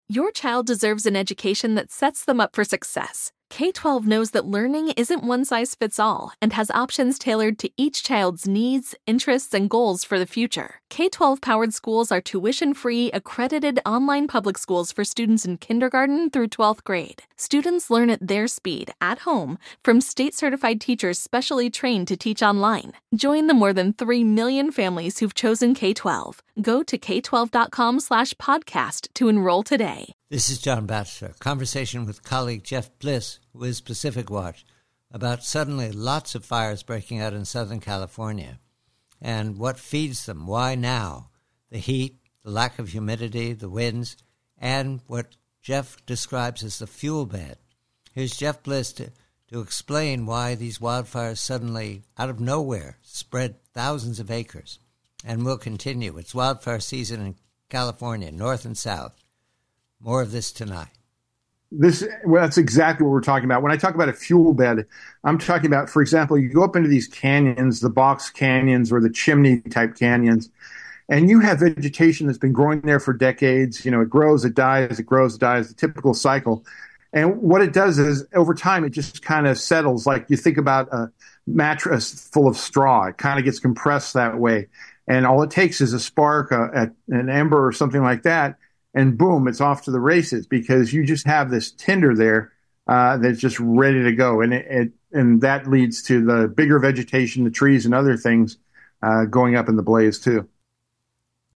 PREVIEW: WILDFIRES: CALIFORNIA: Conversation